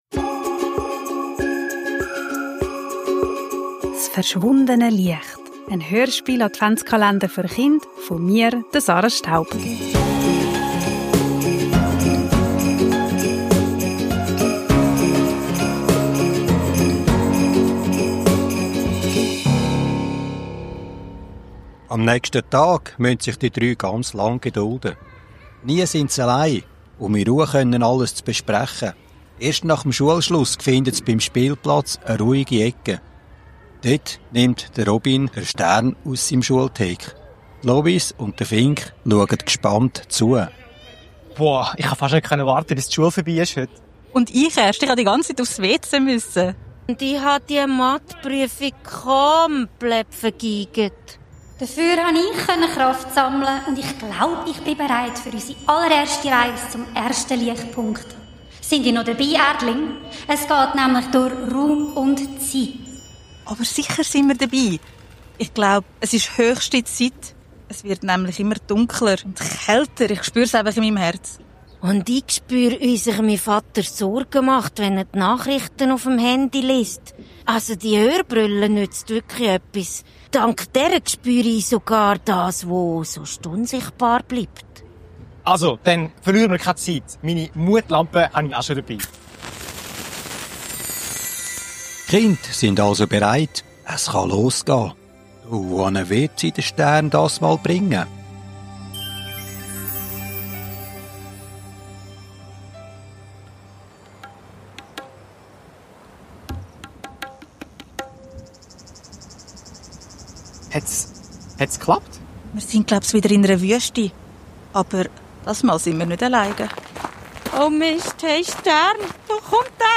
Kinder, Advent, Hörspiel, Weihnachten, Kindergeschichte